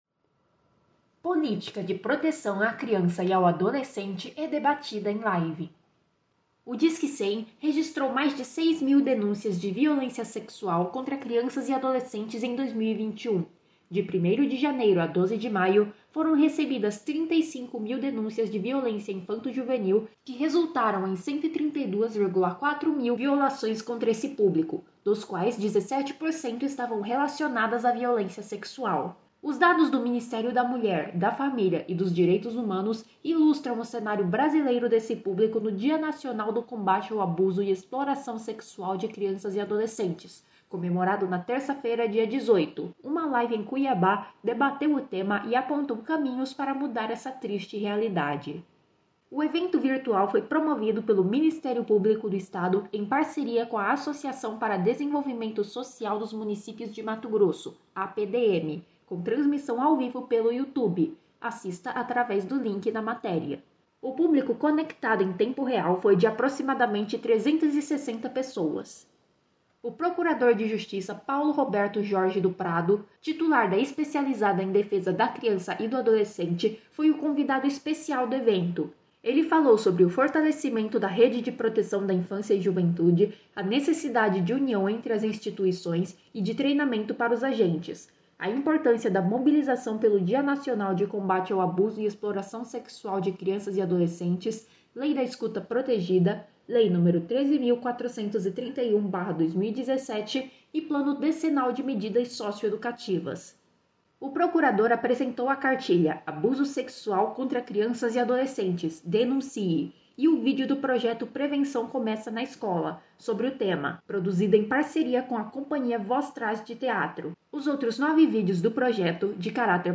Política de proteção à criança e ao adolescente é debatida em live
O evento virtual foi promovido pelo Ministério Público do Estado em parceria com a Associação para Desenvolvimento Social dos Municípios de Mato Grosso (APDM), com transmissão ao vivo pelo YouTube (assista aqui).